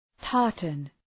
{‘tɑ:rtən}
tartan.mp3